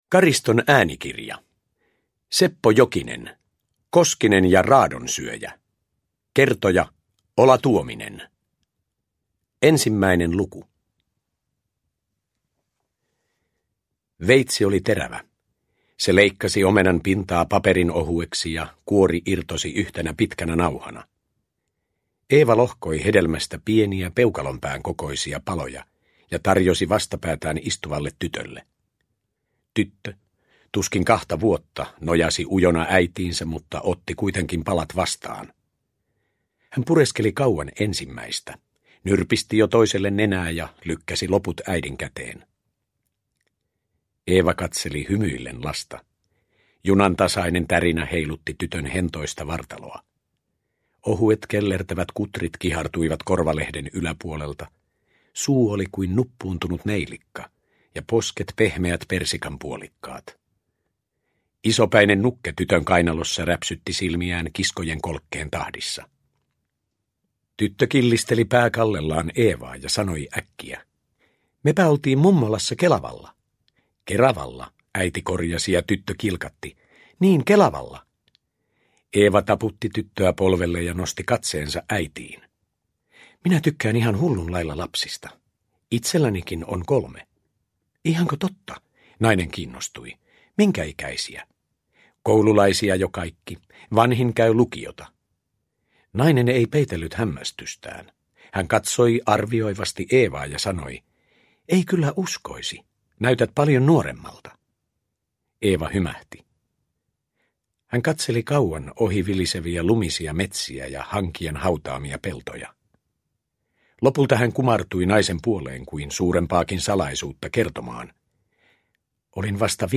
Koskinen ja raadonsyöjä – Ljudbok – Laddas ner